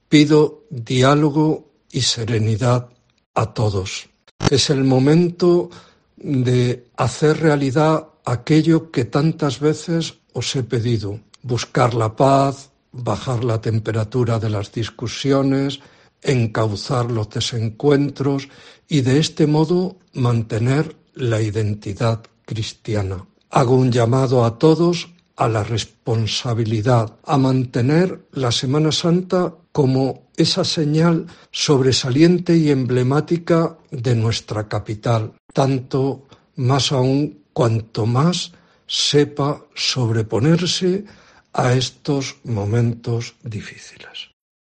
El Obispo de Zamora, sobre la dimisión de la directiva de la Junta Pro Semana Santa